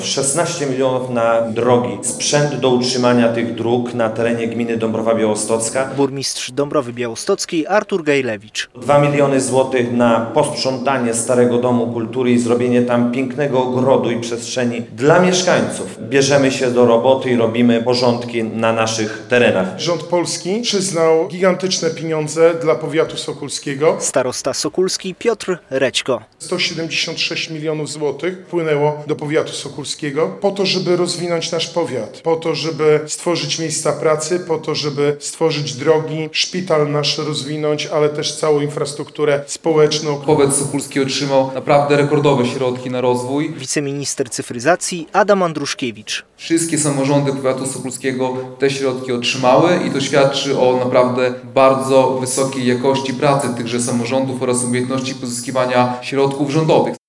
Samorządowcy w Sokółce dziękowali za rządowe wsparcie - relacja